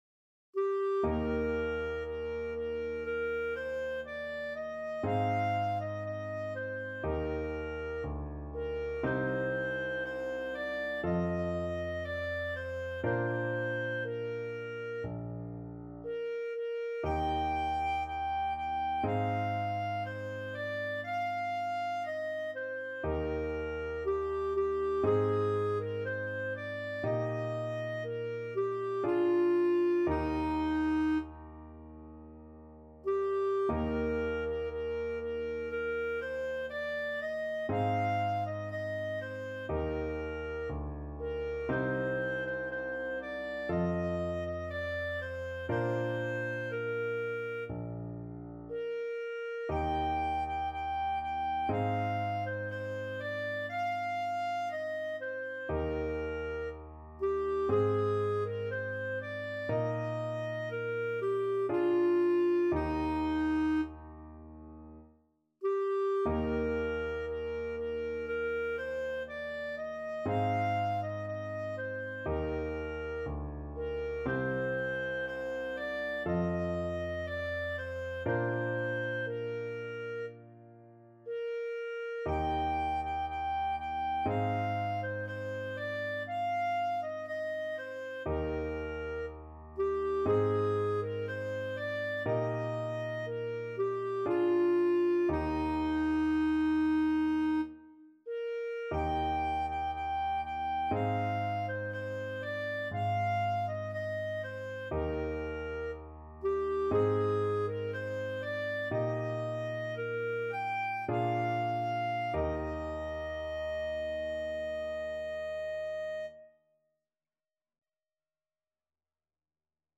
Clarinet version
~ = 100 Adagio
4/4 (View more 4/4 Music)
Classical (View more Classical Clarinet Music)